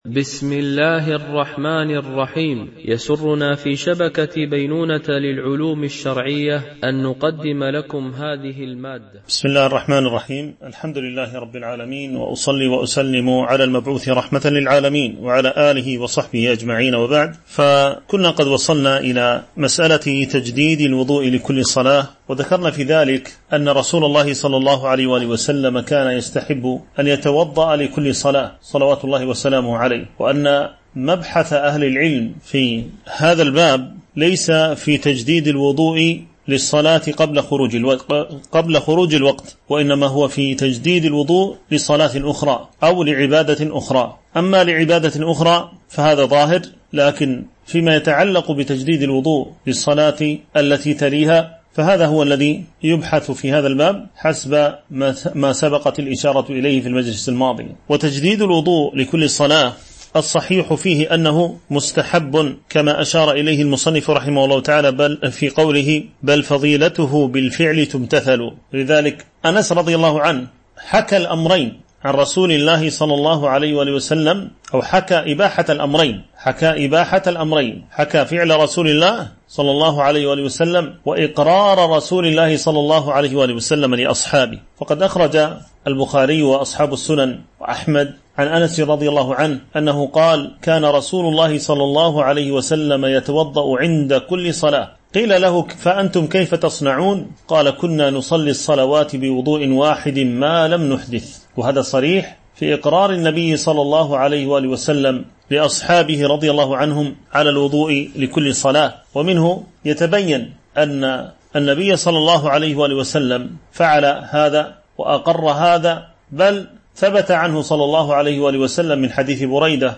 شرح المنظومة اللامية في الناسخ والمنسوخ - الدرس 7
Mono